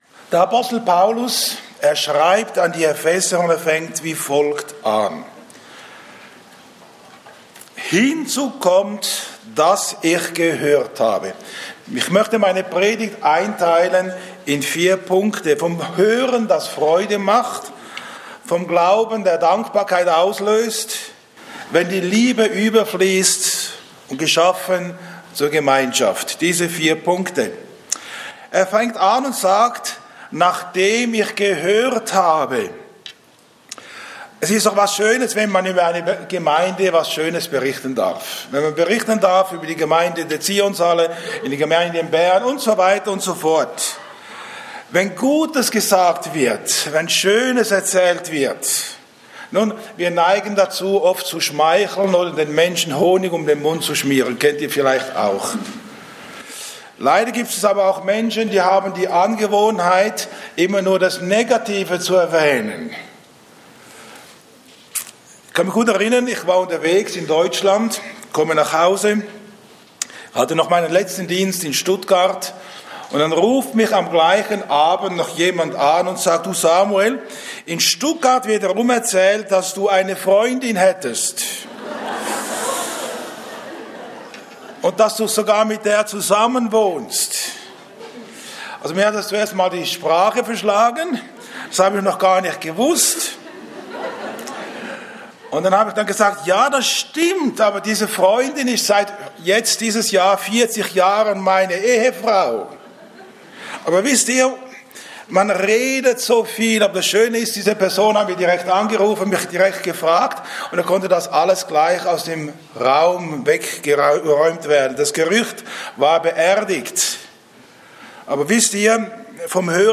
Botschaft Zionshalle